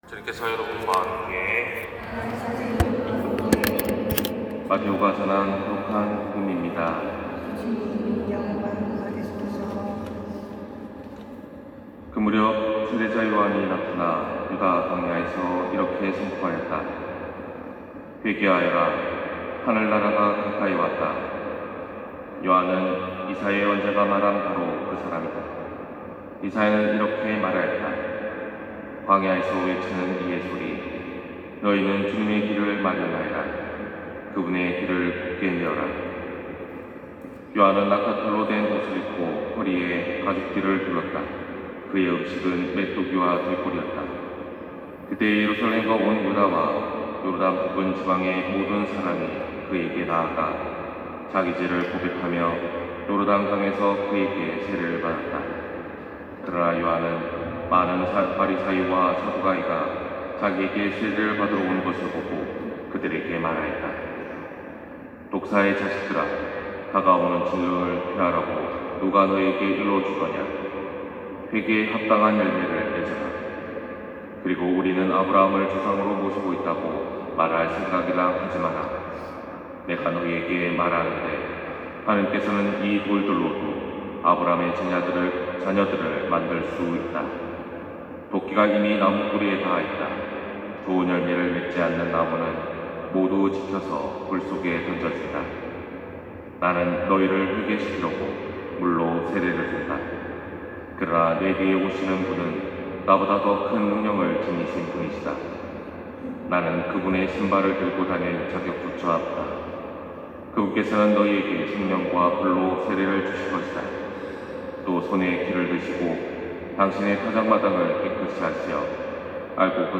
251206신부님강론 말씀